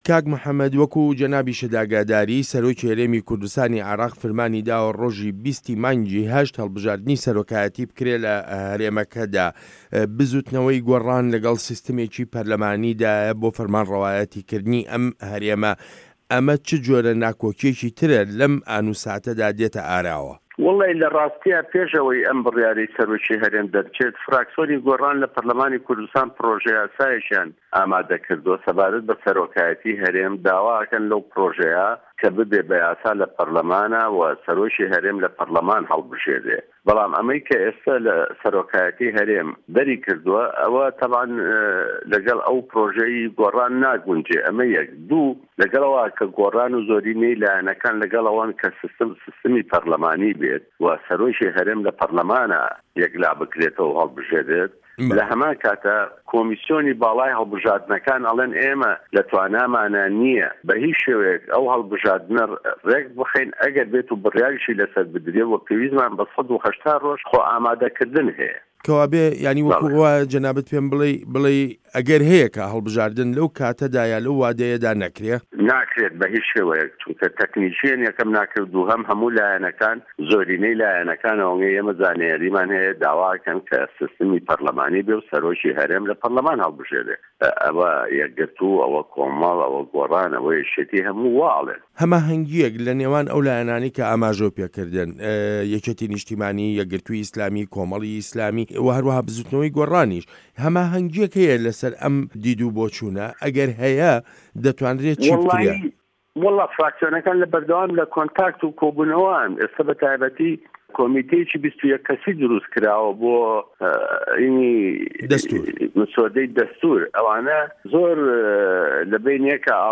وتووێژ له‌گه‌ڵ موحه‌مه‌د تۆفیق ڕه‌حیم